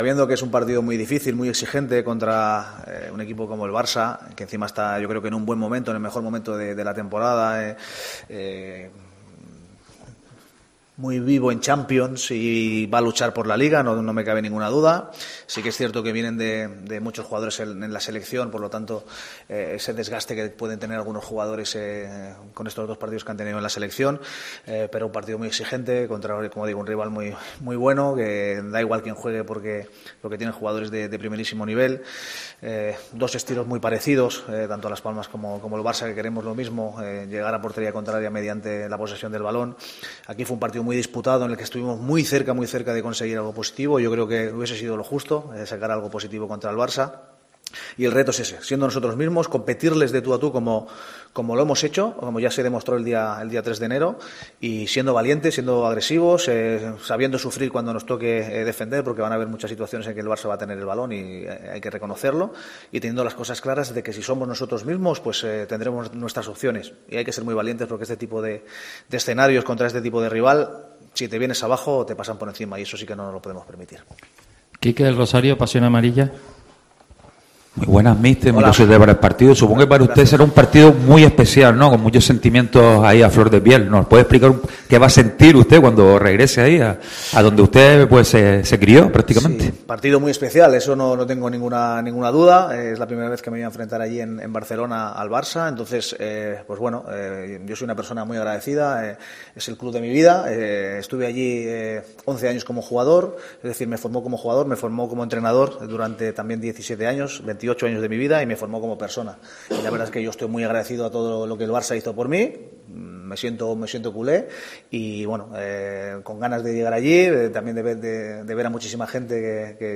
García Pimienta compareció este viernes en la sala de prensa de la Ciudad Deportiva, antes del último entrenamiento que los amarillos llevarán a cabo en Gran Canaria.